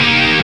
GTR ROCKC0HR.wav